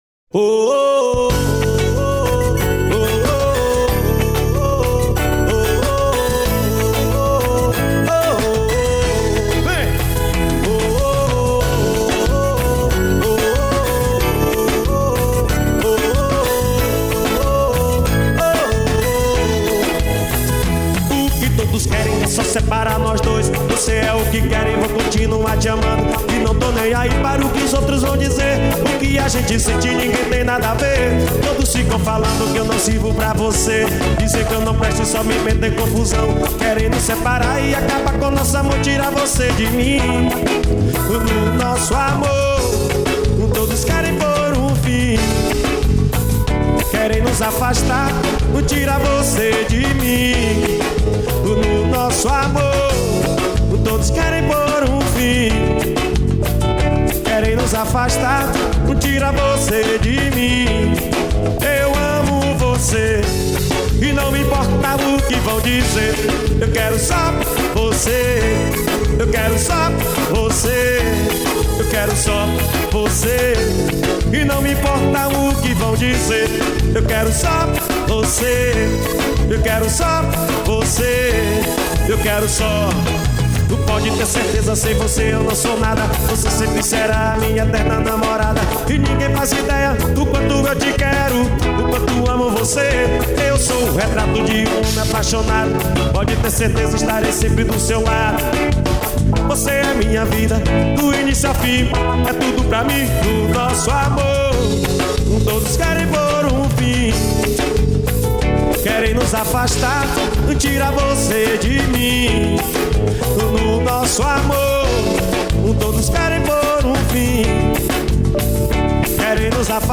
2024-12-30 10:47:33 Gênero: Forró Views